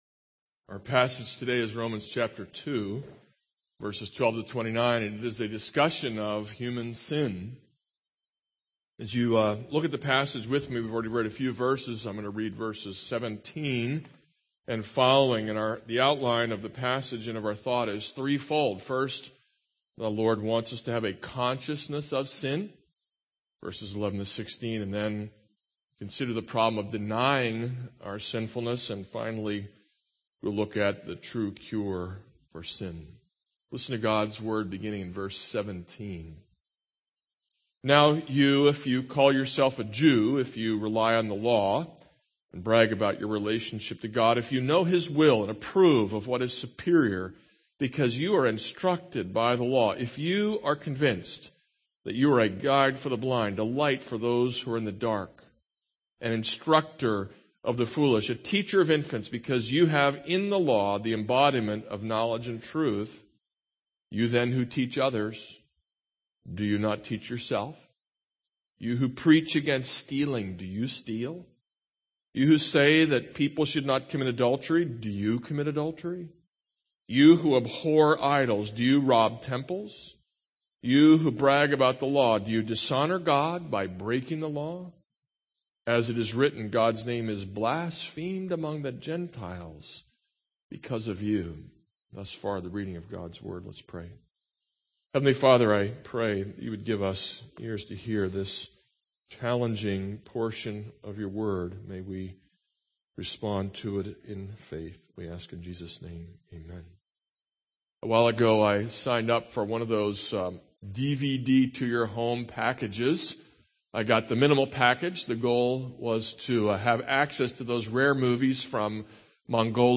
This is a sermon on Romans 2:12-29.